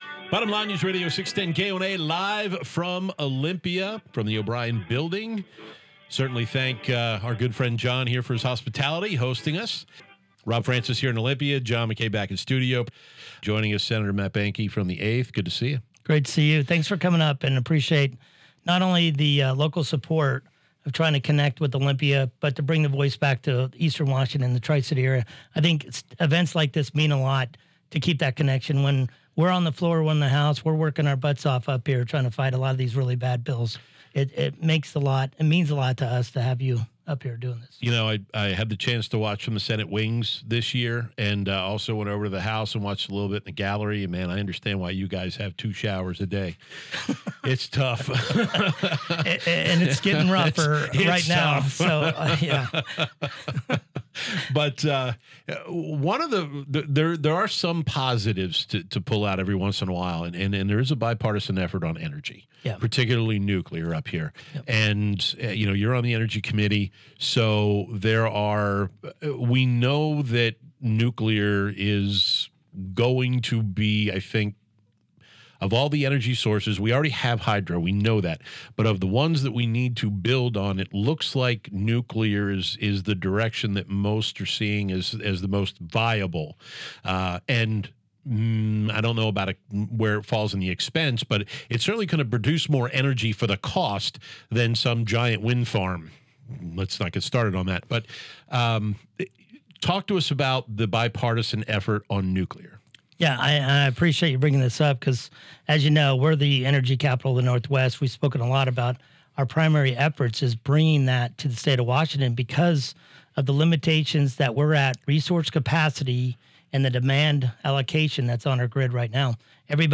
In an interview with KONA, Sen. Matt Boehnke highlighted the bipartisan push for nuclear energy expansion in Washington, particularly in Eastern Washington.